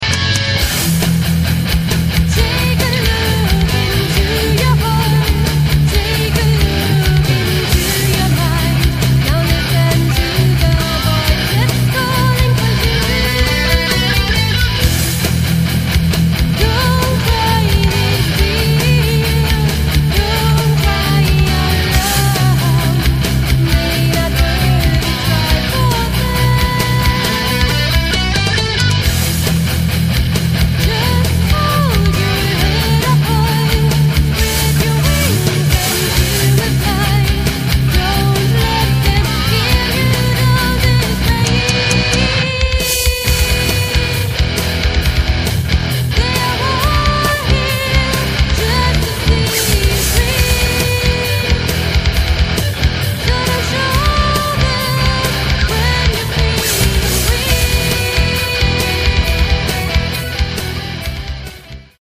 Soundwände gepaart mit ruhigen, melodischen Parts.
Gitarre
Drums
Vocals